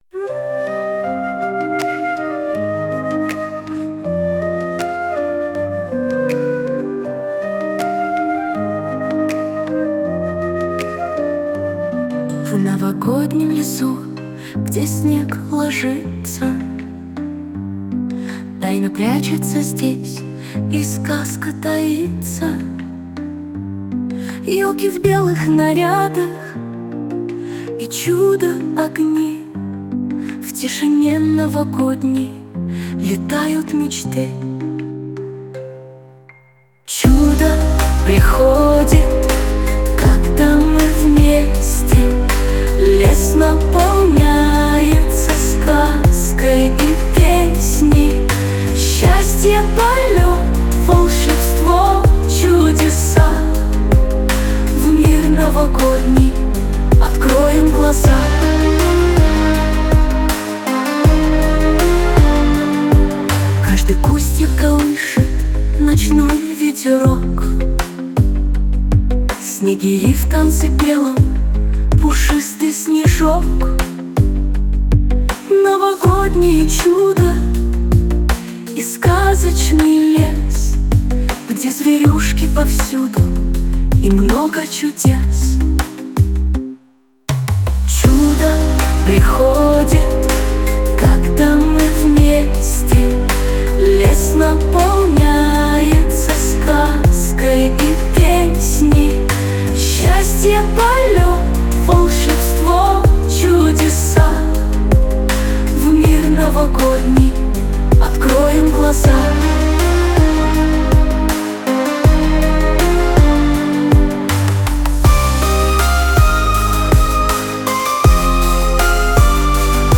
медленный